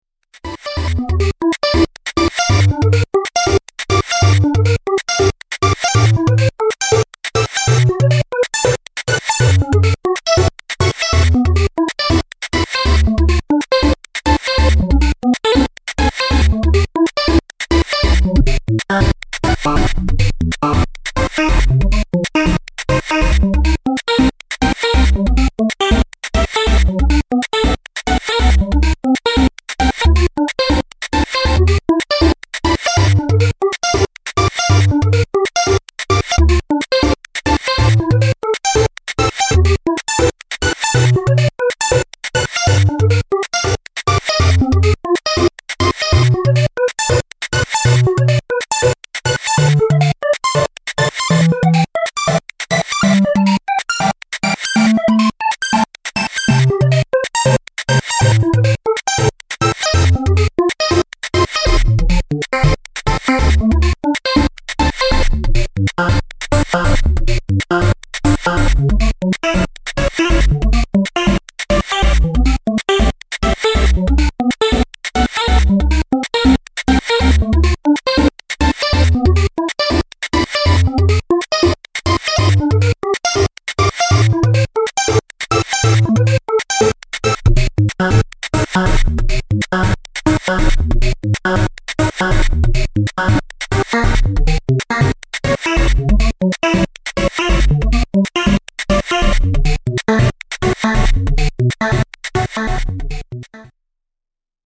EDMアップテンポ激しい